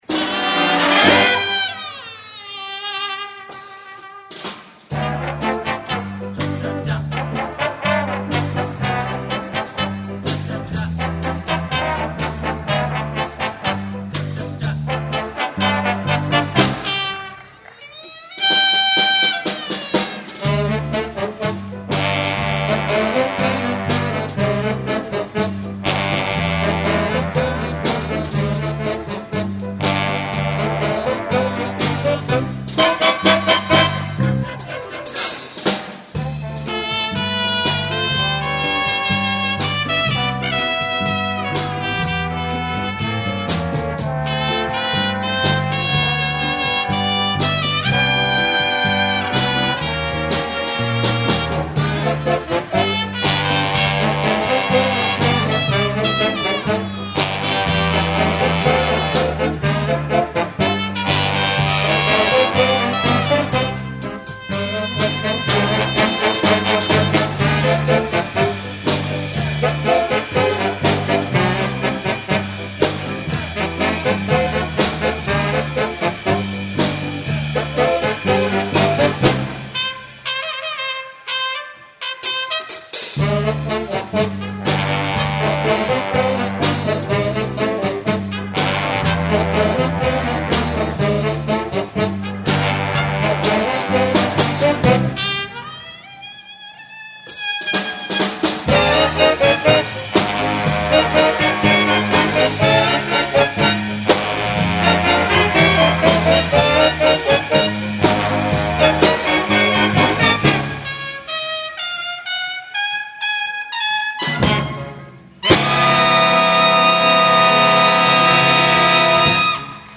2008-03-16 桜井サクライ市民シミン会館カイカン ギンオンじゃず楽団ガクダン
(ダイ26カイ公民コウミンカンマツ)